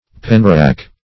Penrack \Pen"rack`\, n. A rack for pens not in use.